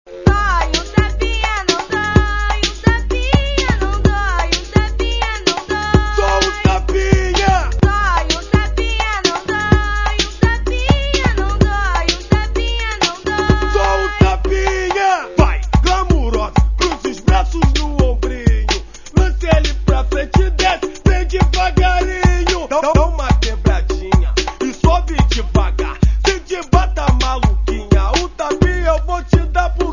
La répèt' finit en bal avec DJ. On arrive vite au funk mais attention, du funk brésilien : des paroles et chorégraphies plutôt explicites, voir salaces.